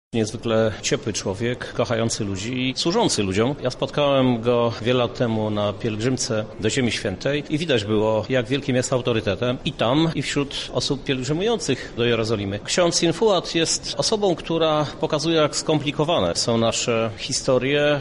Uroczystość odbyła się w Trybunale Koronnym.
• mówi Prezydent Lublina Krzysztof Żuk.